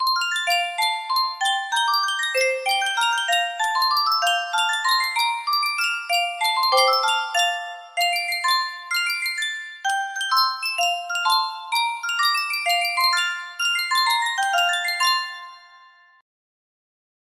Yunsheng Custom Tune Music Box - Glade Jul and Stille Nacht music box melody
Full range 60